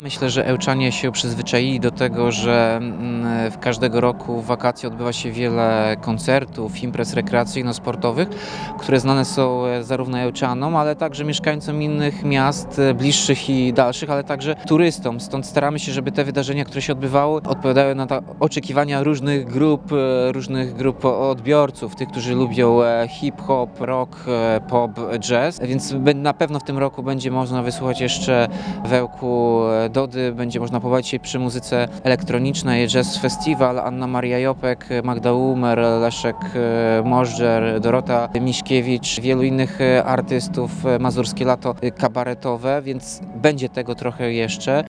Sylwia Grzeszczak zagrała w ramach Mazurskiego Lata Muzycznego. Do końca wakacji pozostało jednak jeszcze 1,5 miesiąca i z pewnością dobrej muzyki w Ełku nie zabraknie- mówił Radiu 5 Tomasz Andrukiewicz, prezydent Miasta.